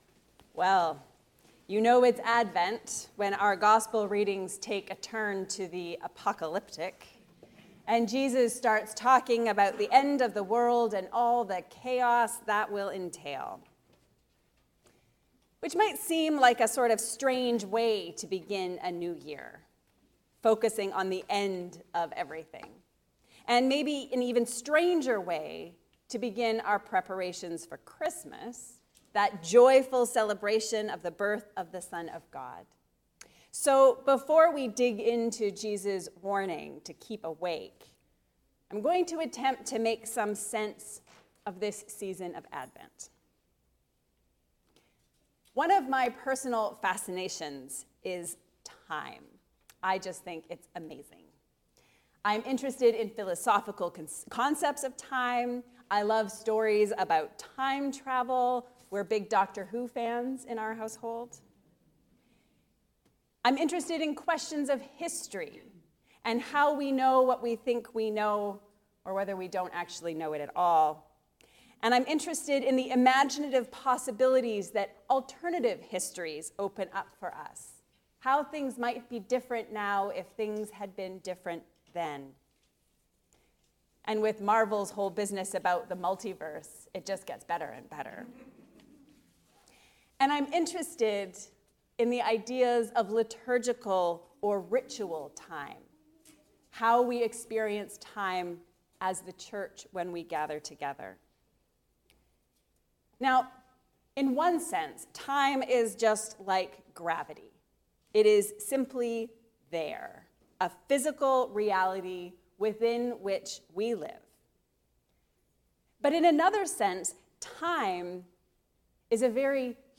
The day of hope is coming. A sermon on the 1st Sunday of Advent